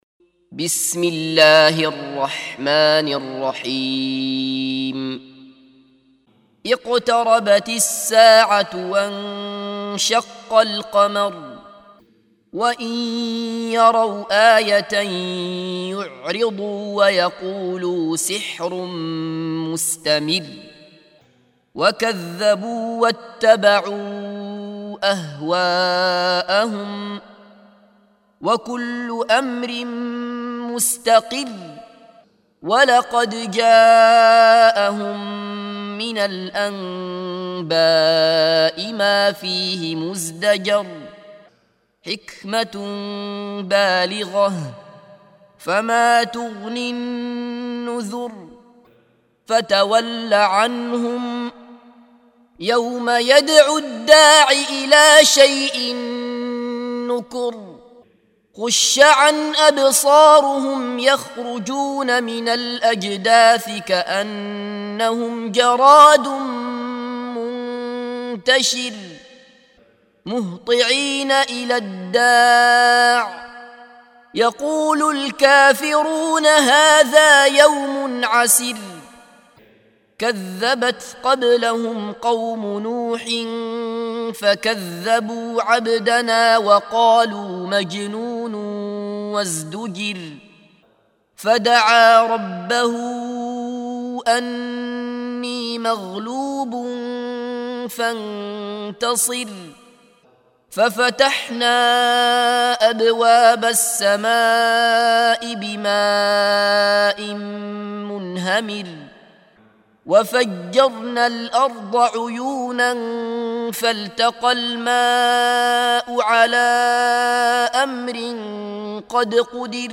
سُورَةُ القَمَرِ بصوت الشيخ عبدالله بصفر